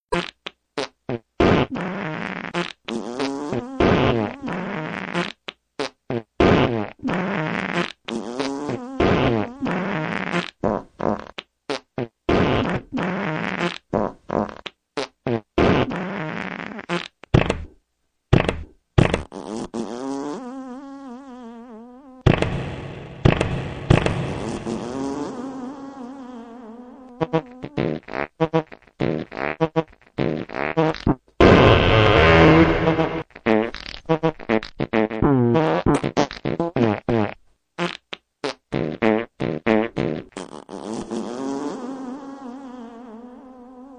FART 2